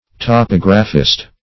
Topographist \To*pog"ra*phist\, n.